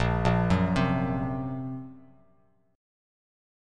A#sus2#5 chord